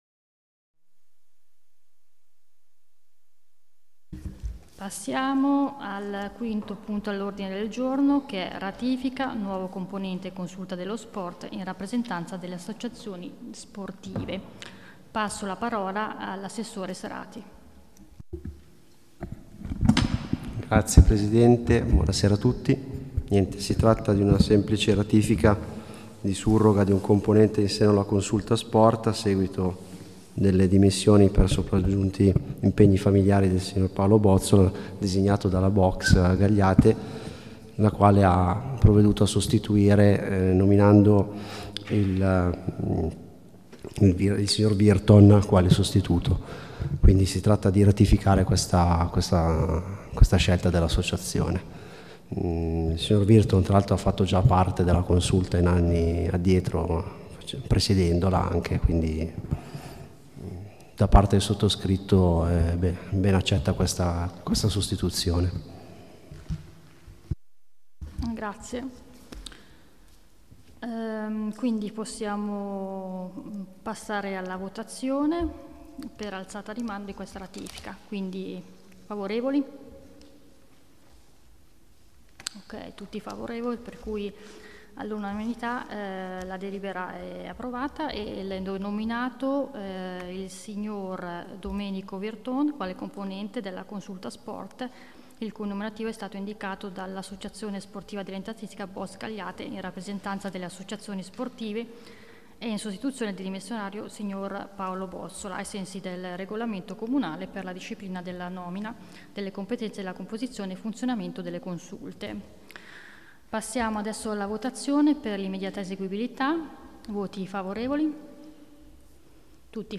Il giorno 29/09/2022 si è riunito il Consiglio Comunale in seduta pubblica presso la sala consiliare del Castello Visconteo Sforzesco.Nel corso della seduta sono stati dibattuti i seguenti punti all'ordine del giorno: per ogni punto è possibile ascoltare il file audio della discussione e delle decisioni prese.